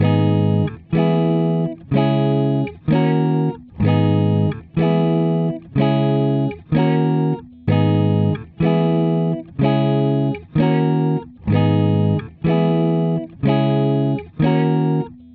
Tag: 125 bpm Trap Loops Guitar Acoustic Loops 2.58 MB wav Key : Unknown